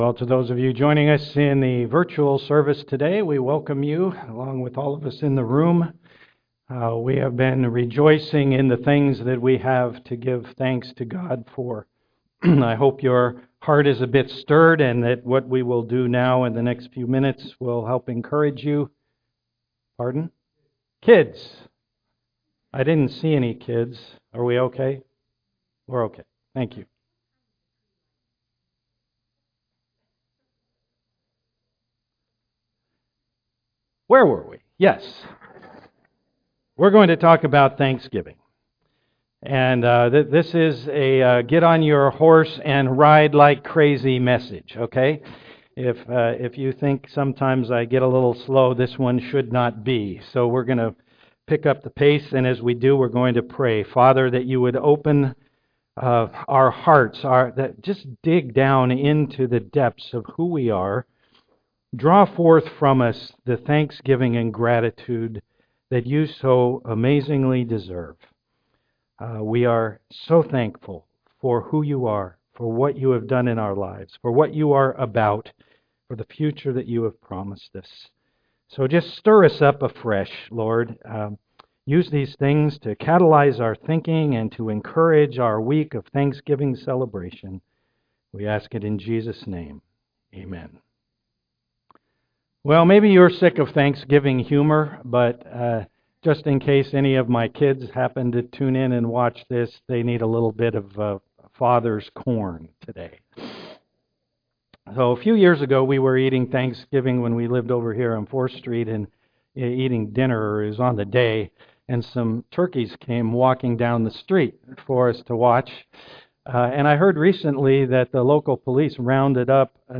Special Days Service Type: am worship Many of us love Thanksgiving as a holiday more than a life-style.